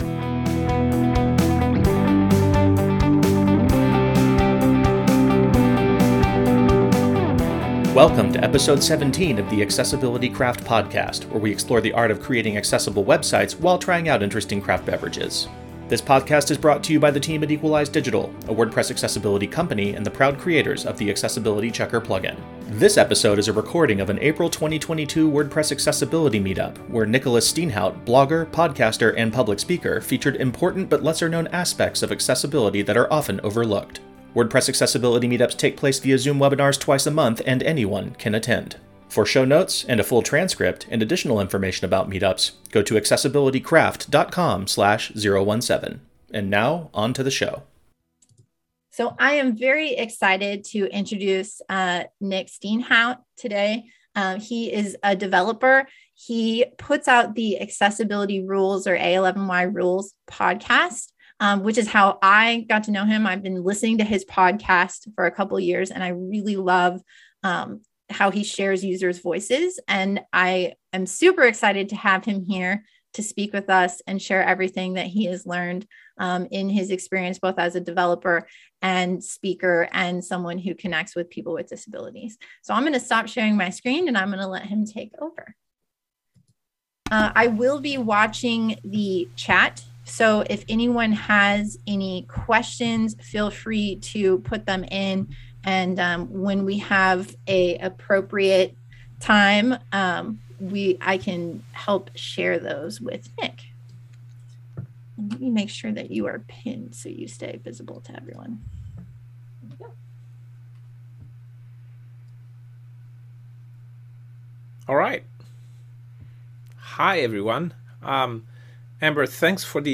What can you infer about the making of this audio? WordPress Accessibility Meetups take place via Zoom webinars twice a month, and anyone can attend.